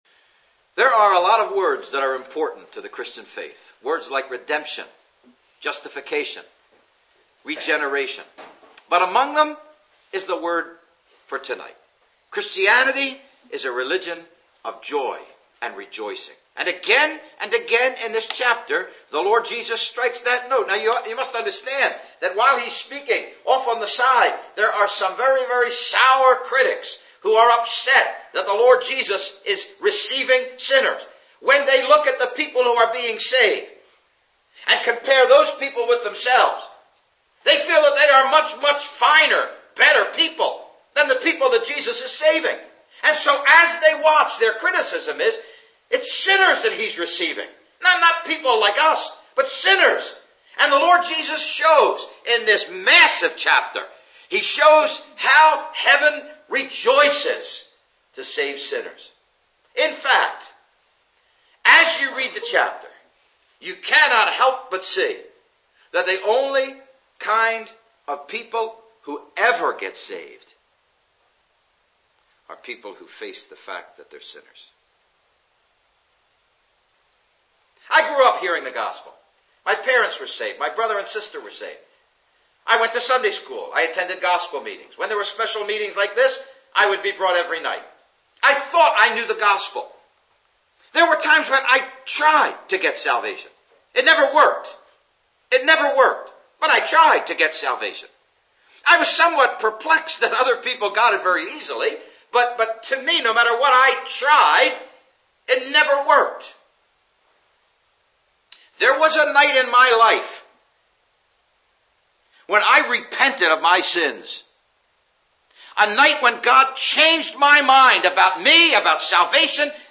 (Message preached 8th Nov 2008 in Ambrosden Village Hall)